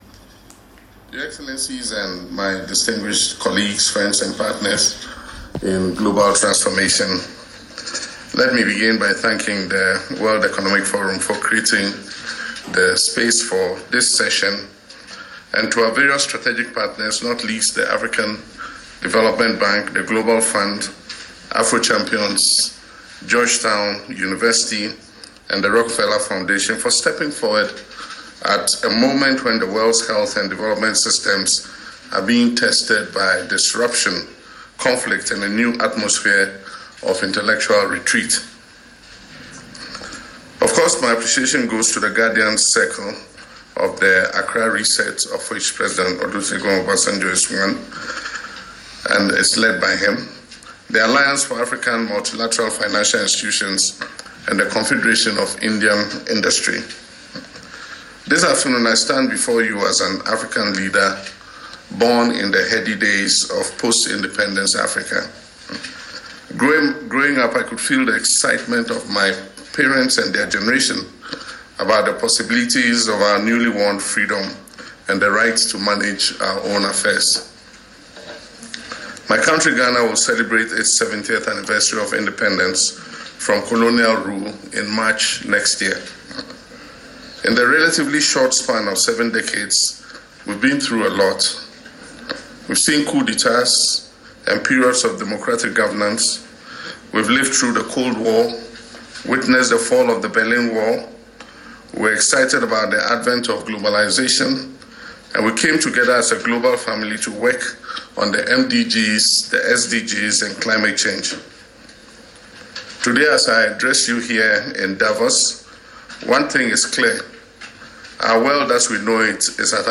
He made the call while addressing a high-level Davos Convening of the Accra Reset Initiative on the sidelines of the World Economic Forum in Switzerland on Thursday 22nd January.